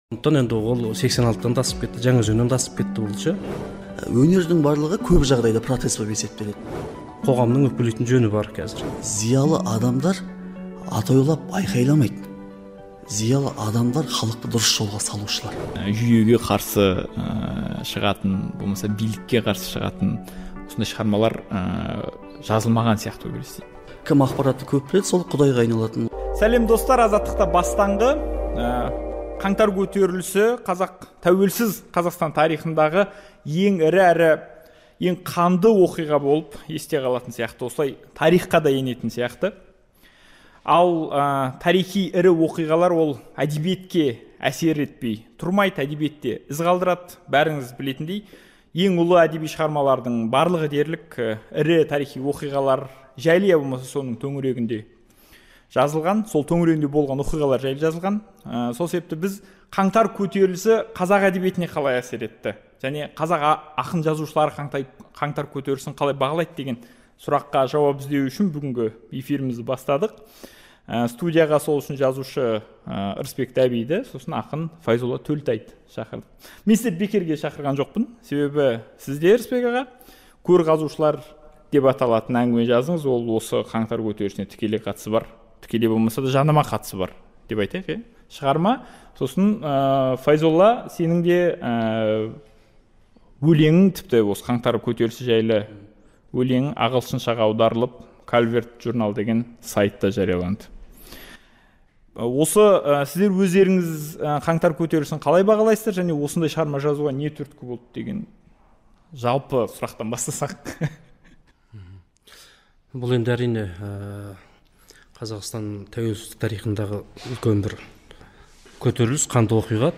"Бастаңғыда" Алматыдағы қантөгіске ұласқан "Қаңтар көтерілісі" не себепті болды, билік одан сабақ алды ма, террористер болды ма, жоқ па, өзгеріс болады деп үміт артуға бола ма деген сұрақтарға жауап іздеп көрдік. Студия қонақтары